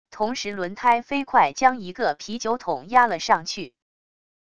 同时轮胎飞快将一个啤酒桶压了上去wav音频